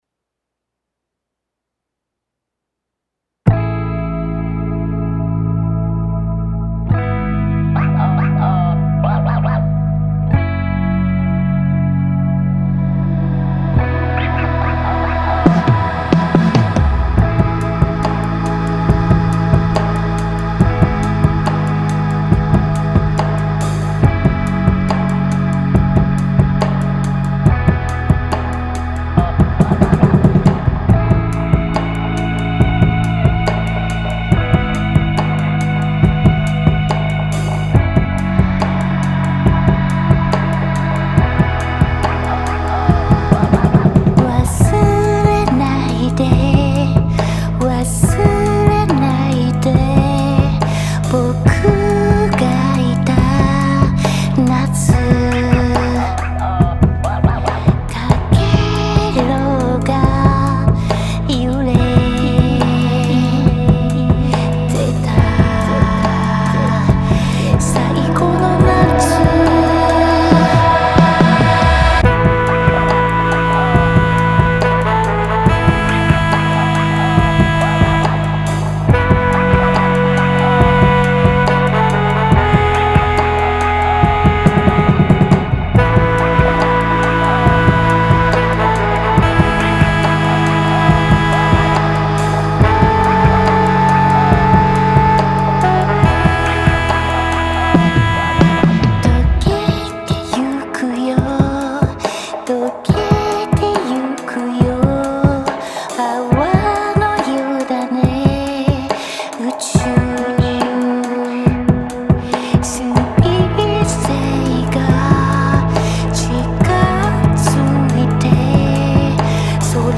(ラフミックス)